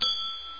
SFX金属碰撞叮的一声音效下载